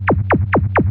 Zapps_05.wav